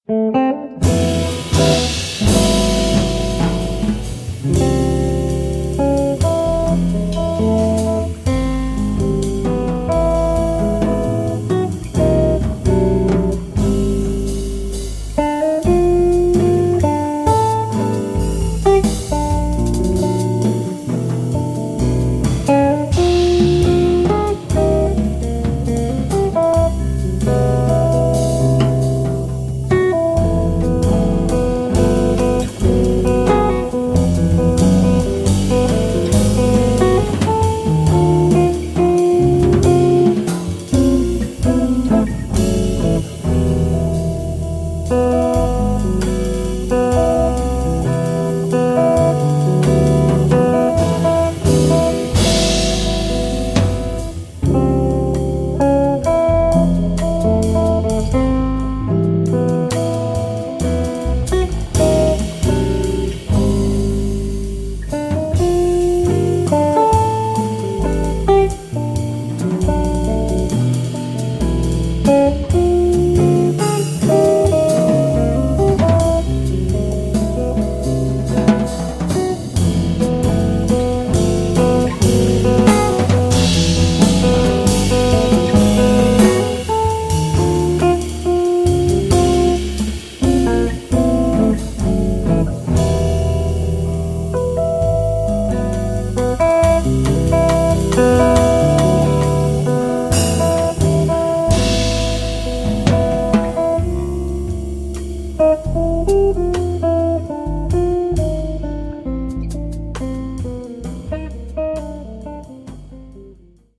chitarra
basso e contrabbasso
batteria